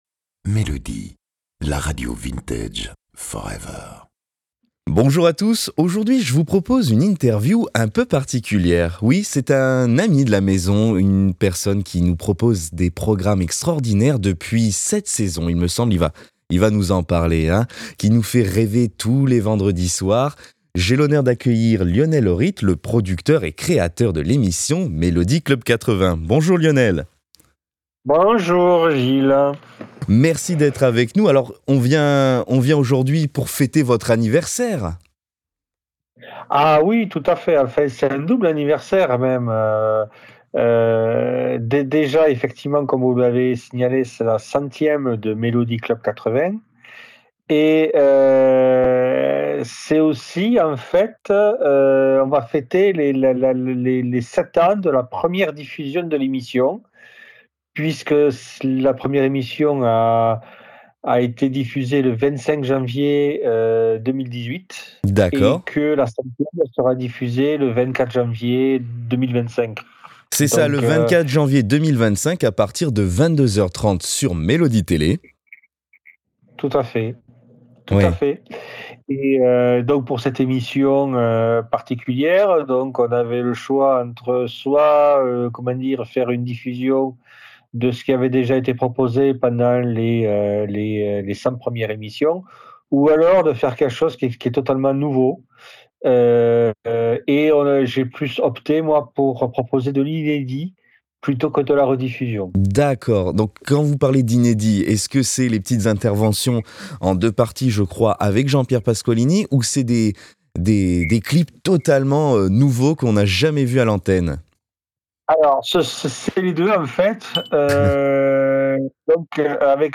Melody Club 80 - La 100ème - Interview